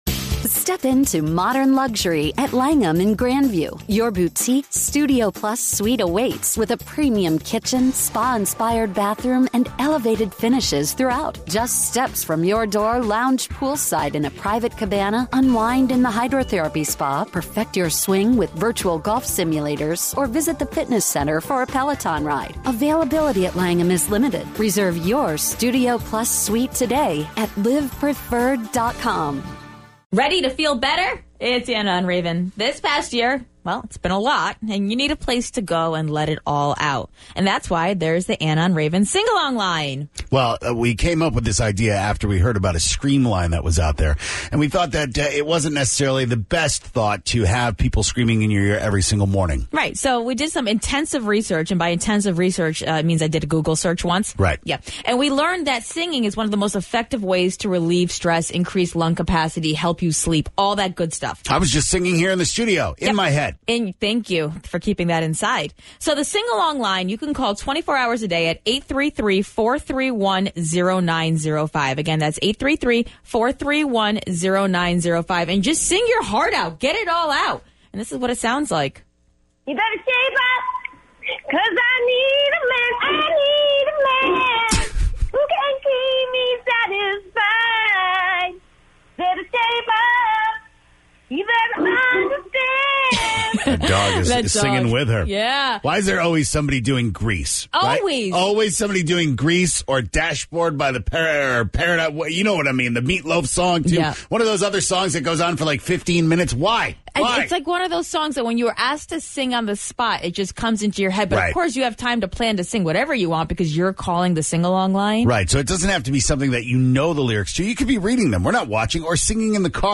Check out who sang their hearts out today in the podcast and you can call and leave a singing voicemail anytime you'd like.